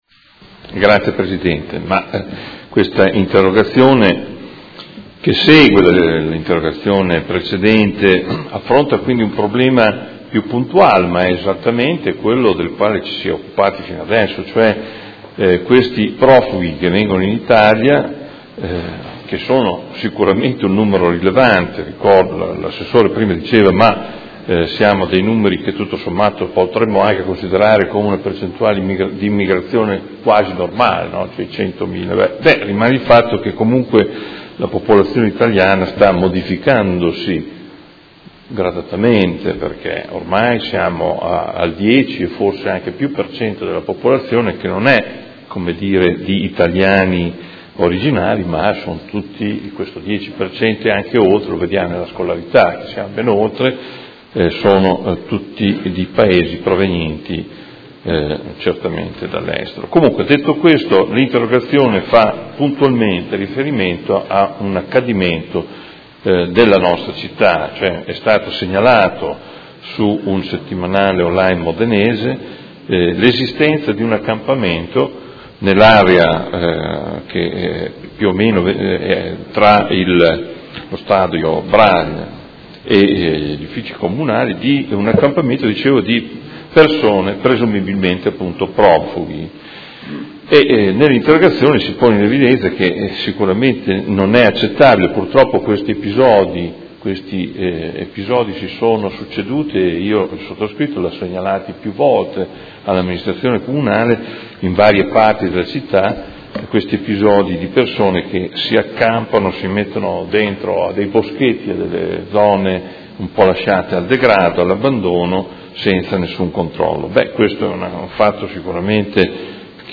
Seduta del 6 ottobre. Interrogazione del Consigliere Morandi (F.I.) avente per oggetto: Accampamento di profughi nell’area verde tra lo Stadio Braglia e gli uffici Comunali; occorrono interventi urgenti